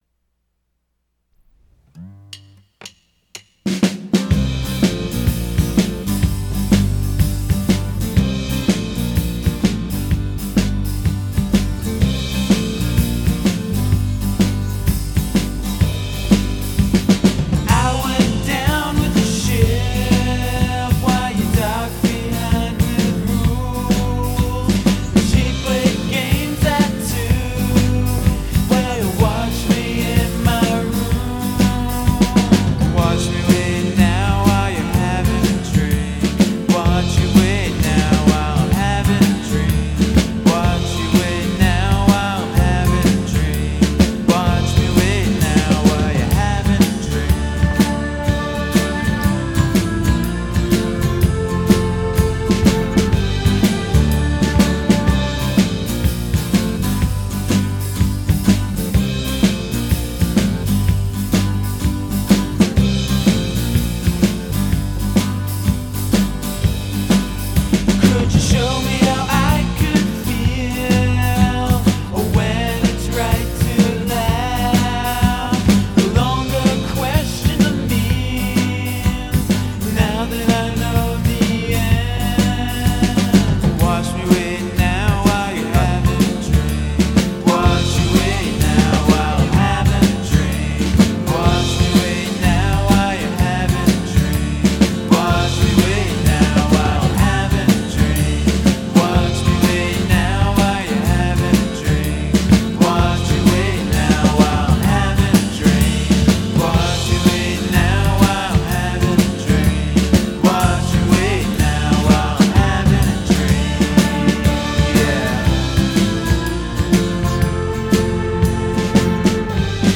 plays Mellotron.
24 track tape